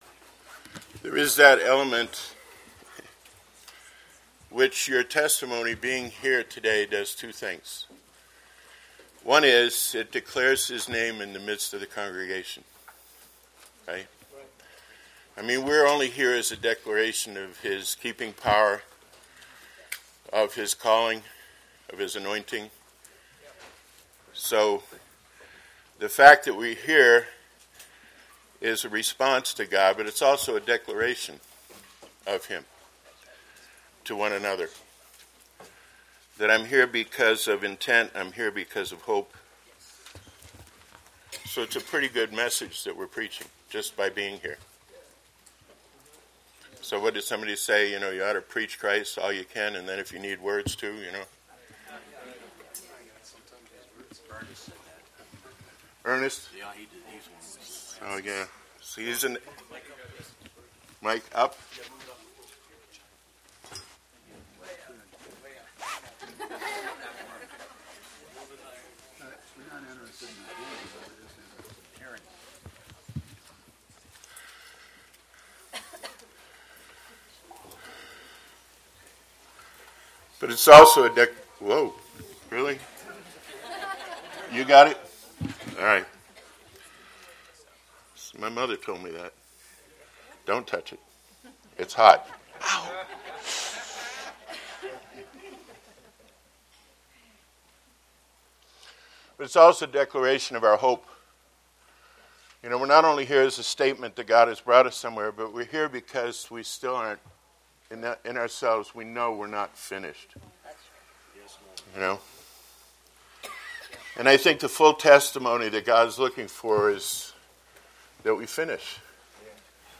Posted in 2017 Shepherds Christian Centre Convention